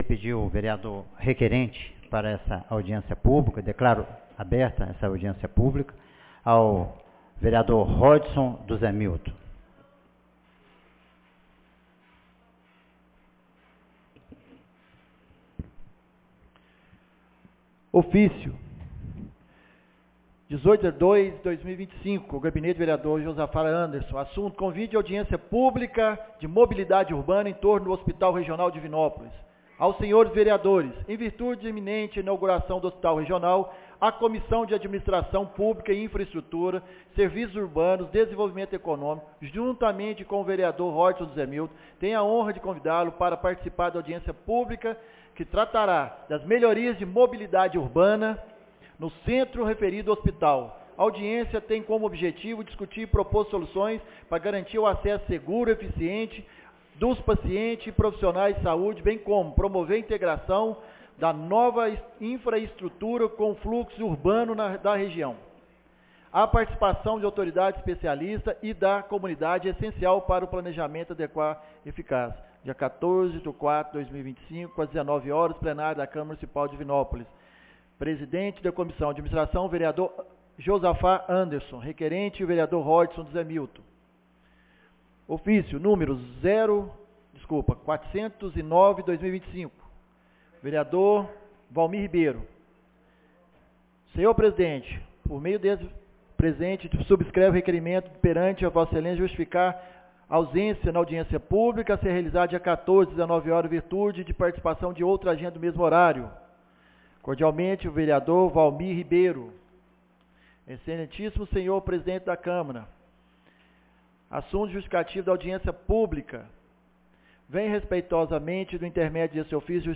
Audiência pública entorno Hospital Regional 14 de abril de 2025